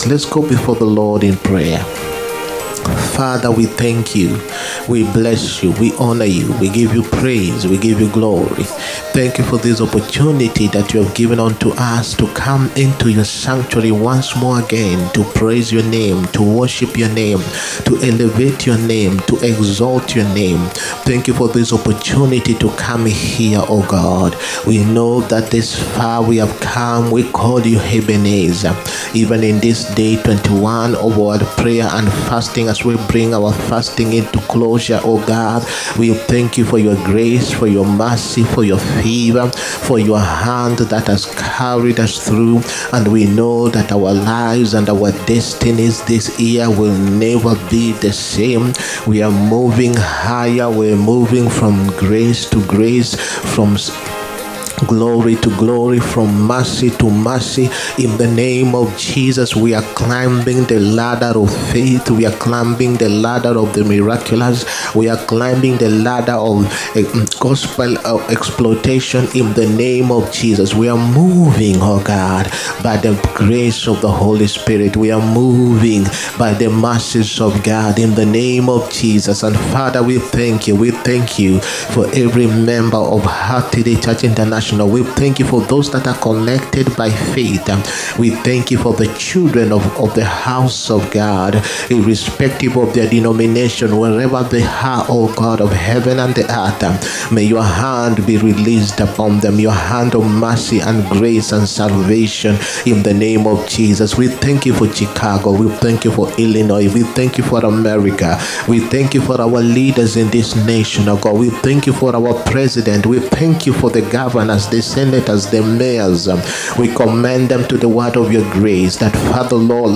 SUNDAY ANOINTING SERVICE. THE POWER OF FAITH. 2ND FEBRUARY 2025.